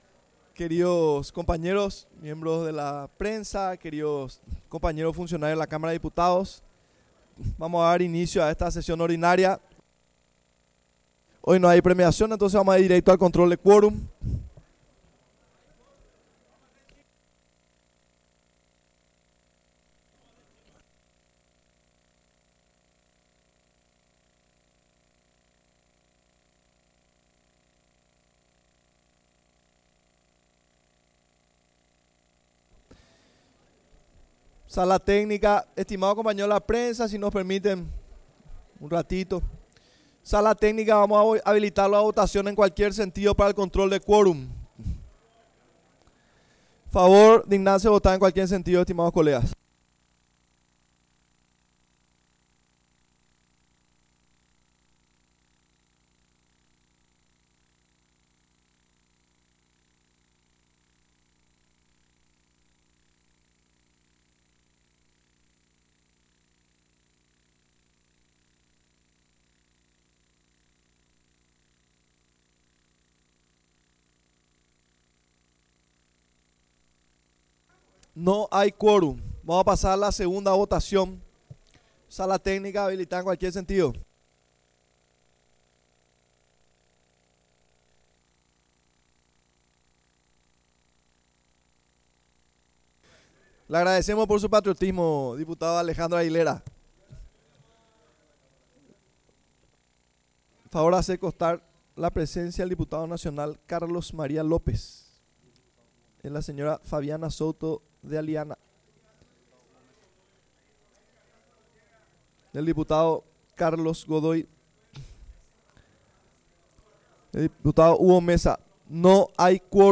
Sesión Ordinaria, 31 de marzo de 2026